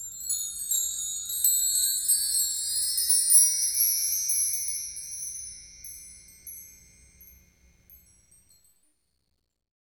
Index of /90_sSampleCDs/Roland LCDP03 Orchestral Perc/PRC_Wind Chimes2/PRC_Marktree